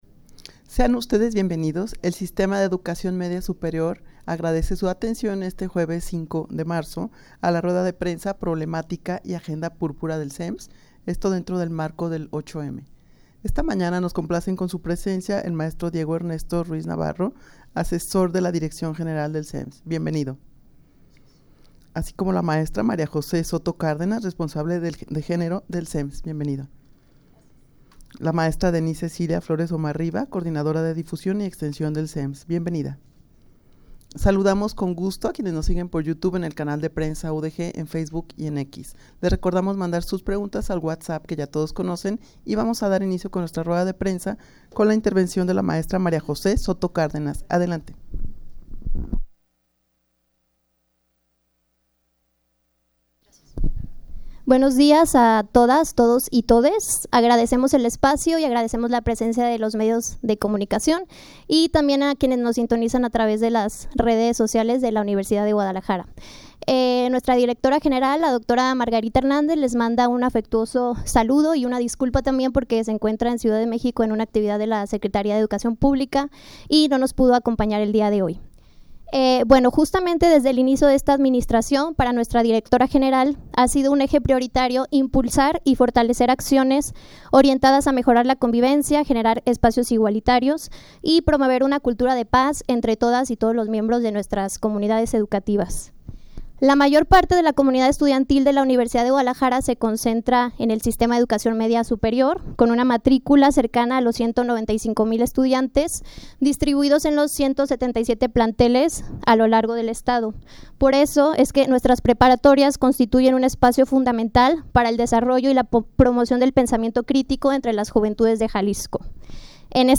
rueda-de-prensa-problematica-y-agenda-purpura-del-sems-en-el-marco-del-8m.mp3